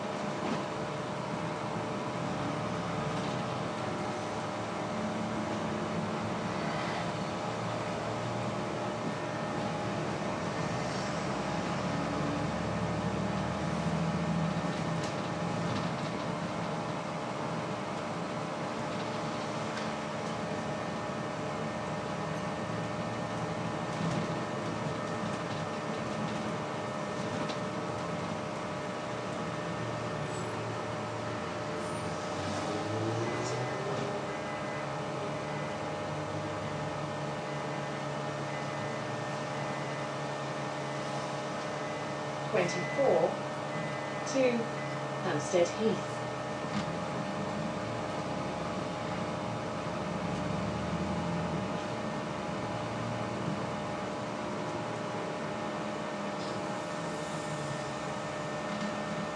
Upstairs: the relaxing sounds of the bus air con